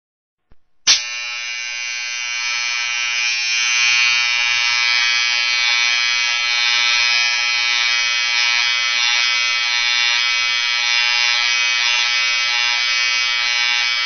جلوه های صوتی
دانلود صدای ماشین ریش تراش 2 از ساعد نیوز با لینک مستقیم و کیفیت بالا